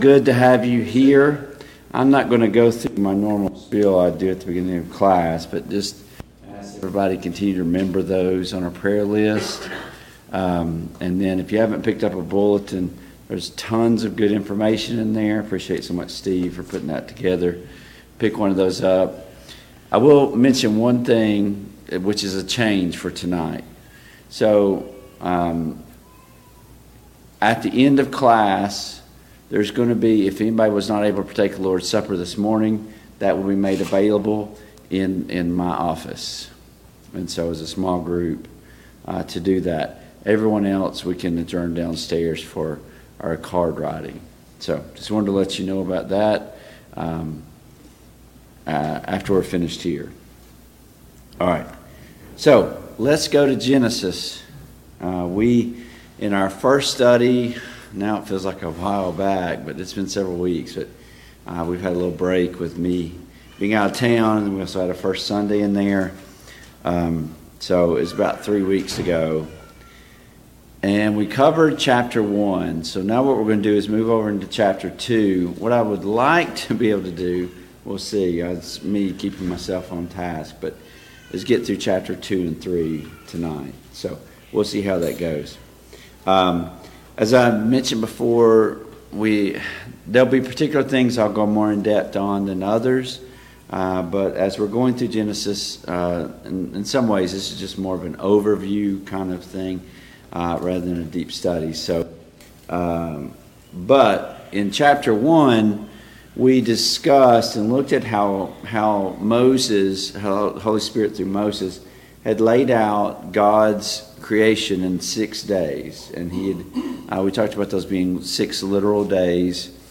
Study of Genesis Passage: Genesis 2:1-25 Service Type: Family Bible Hour « 3.